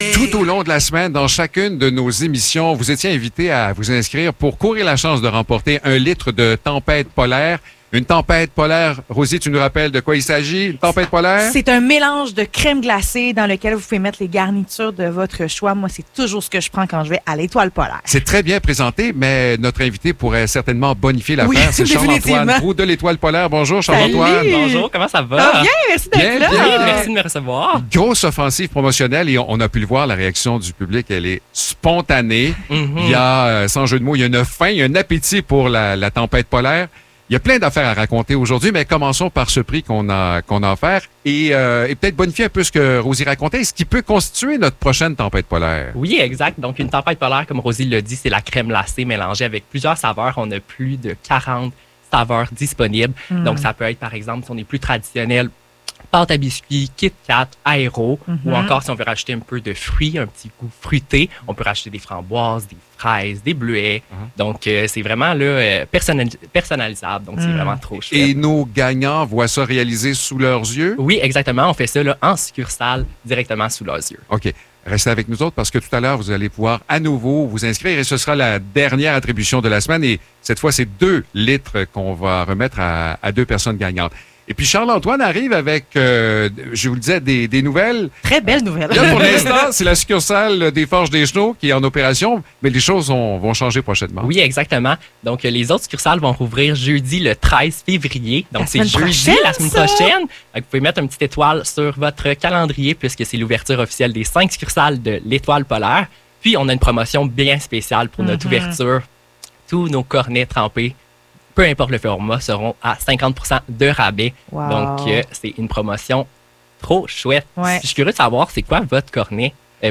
Entrevue avec L’Étoile polaire!